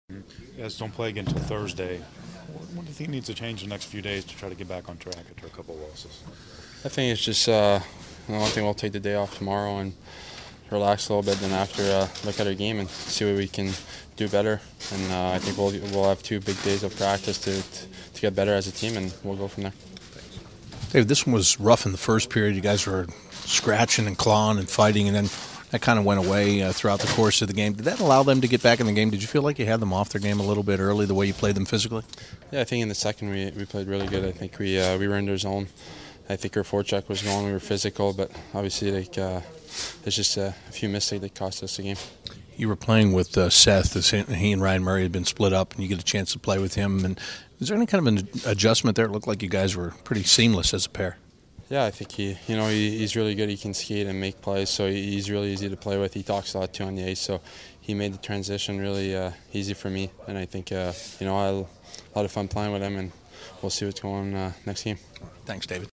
David Savard Post Game Interview 3-13-16